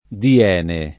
[ di- $ ne ]